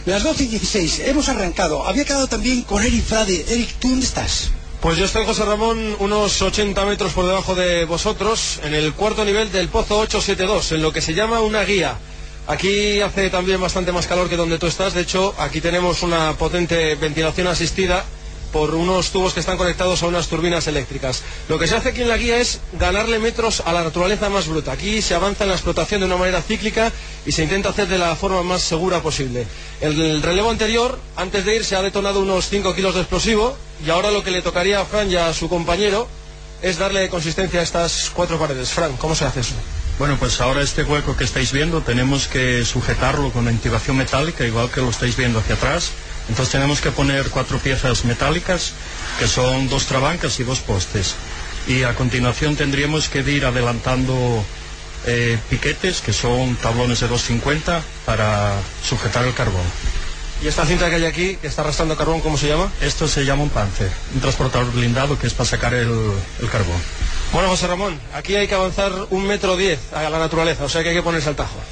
Inolvidable, y más para un asturiano, hacer radio a 500 metros de profundidad, en el Pozo «Nicolasa» de Hunosa, con Fernando Alonso.
Intervención desde la guía del «Nicolasa».